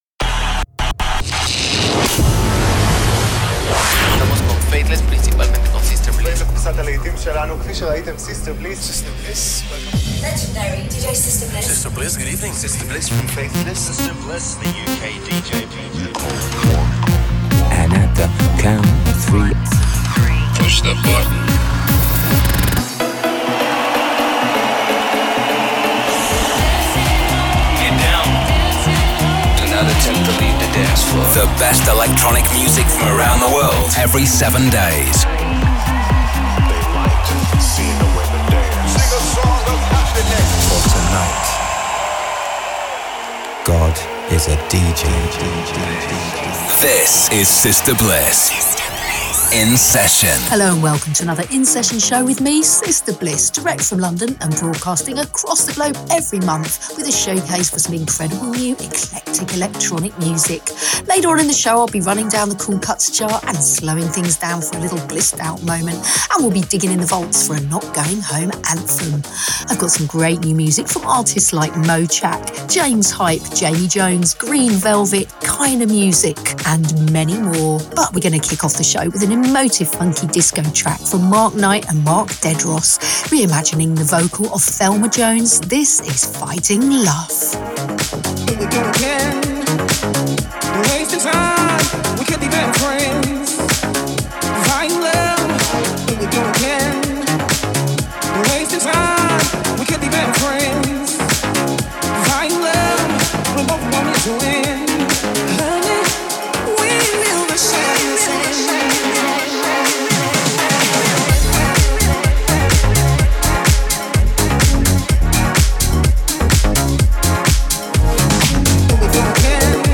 upfront electronic music
a live DJ mix